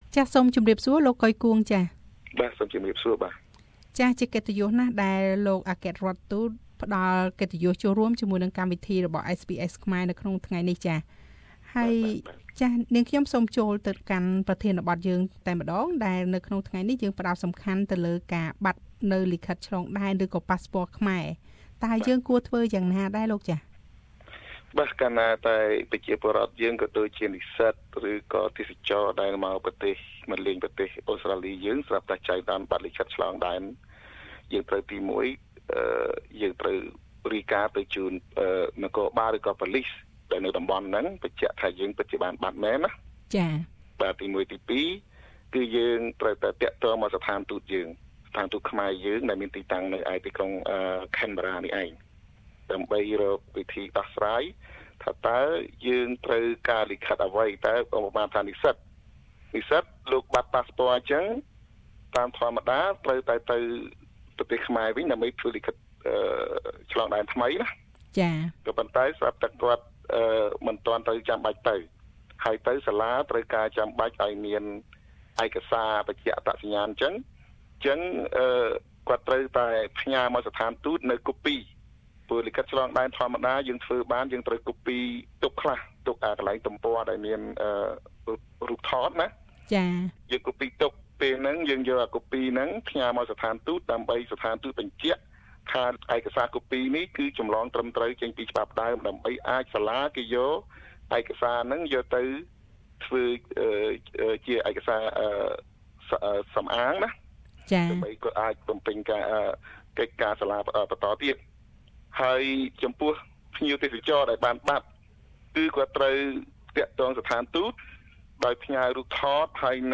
សូមស្តាប់បទសម្ភាសន៍រវាងវិទ្យុ SBS ខ្មែរ ជាមួយនឹងលោក កុយ គួង អគ្គរាជទូតខ្មែរប្រចាំនៅប្រទេសអូស្រ្តាលី និងណូវ៉ែលហ្សេឡង់ដូចតទៅ។